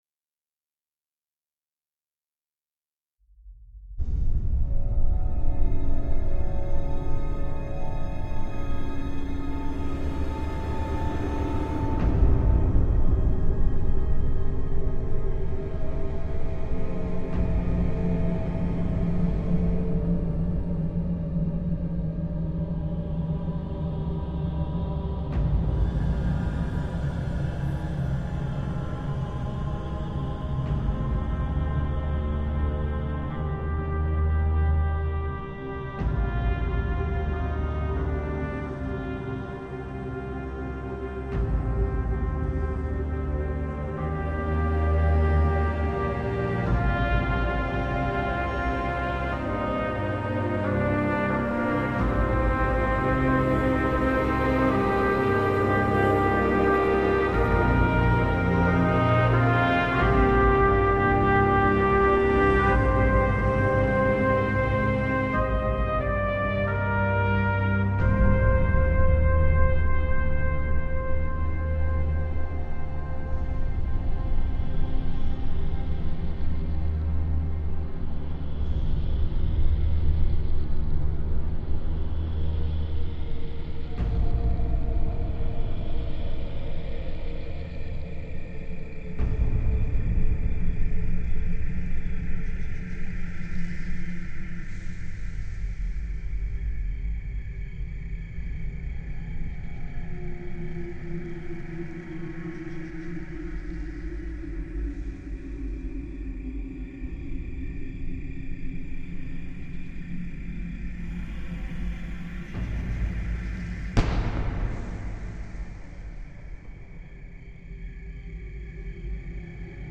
Ambiance sonore
edgen_intruder.mp3